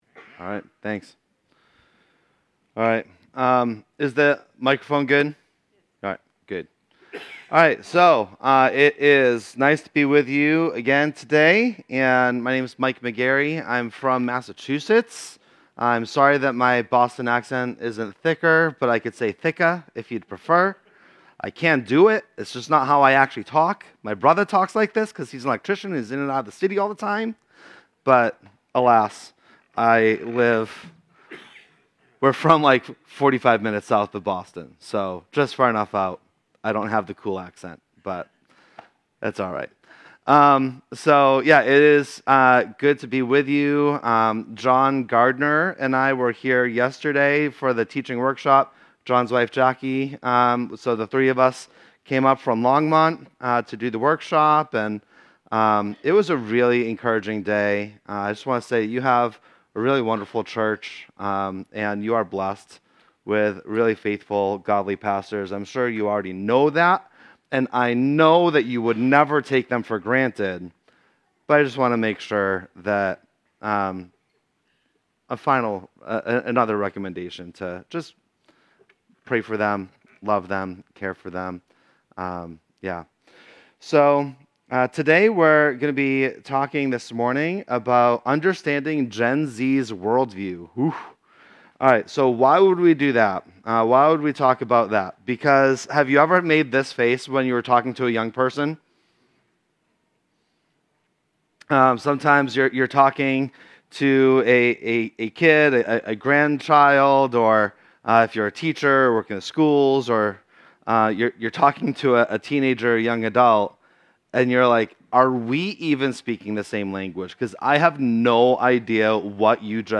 Service Type: Sunday Studies